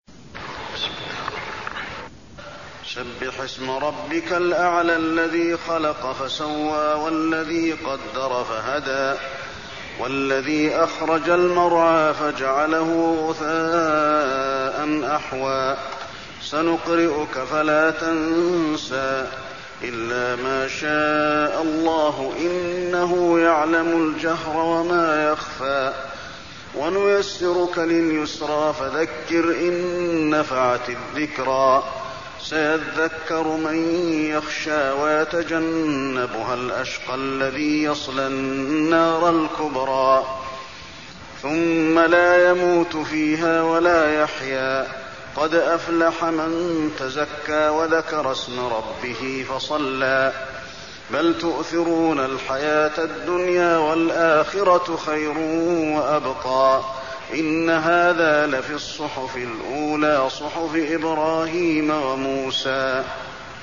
المكان: المسجد النبوي الأعلى